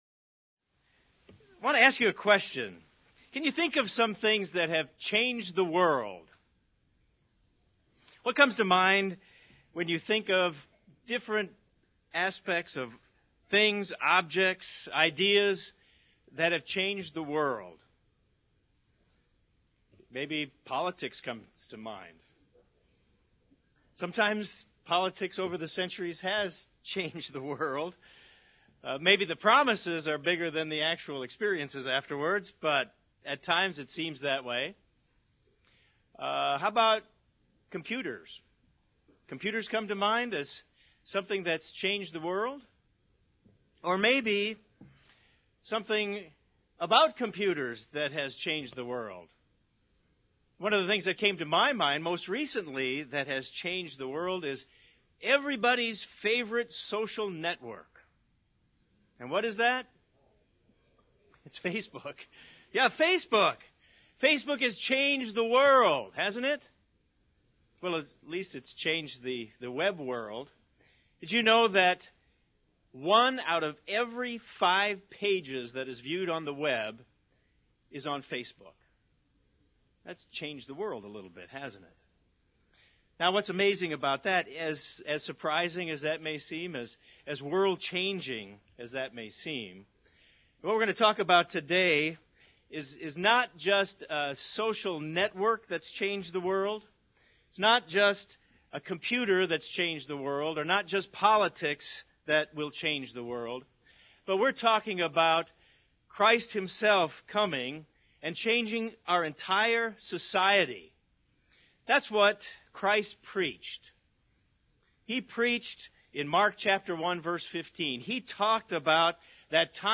kingdom-of-god-bible-seminars-believe-the-gospel_0.mp3